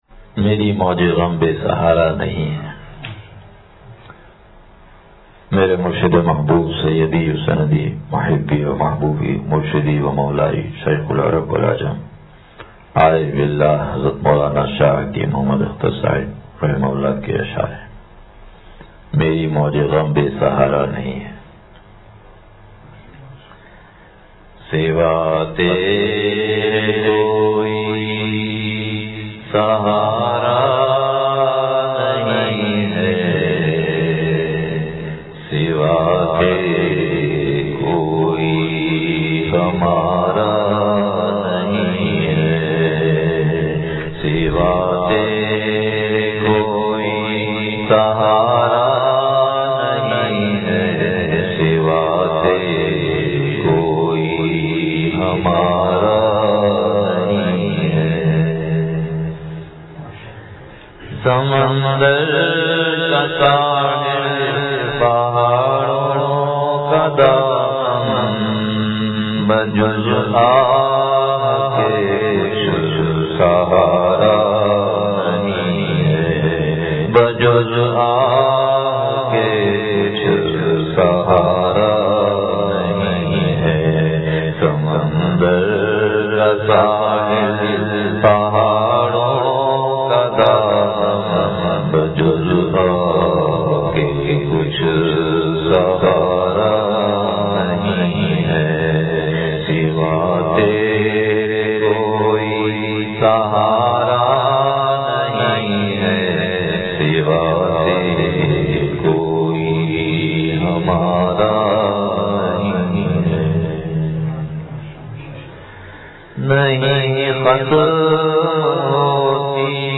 مری موجِ غم بے سہارا نہیں ہے – مجلس بروز اتوار